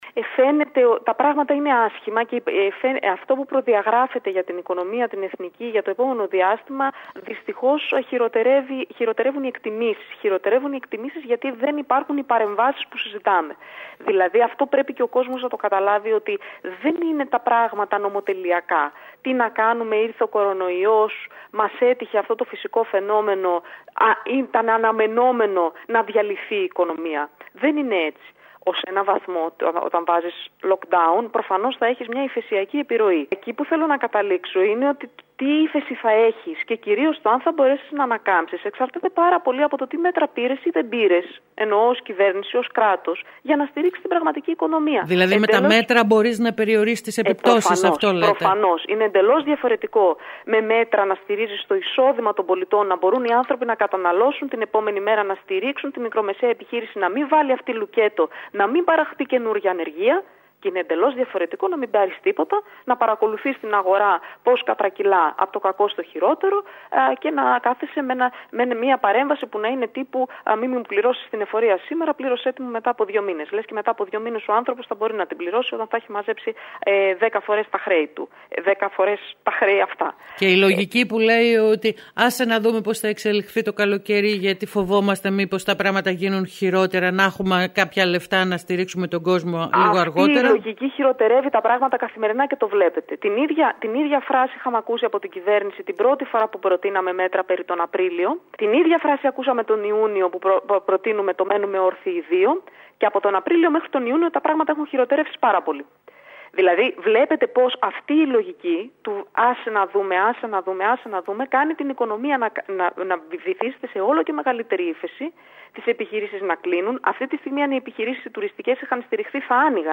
Κέρκυρα: Η Έφη Αχτσιόγλου μιλά στην ΕΡΤ ΚΕΡΚΥΡΑΣ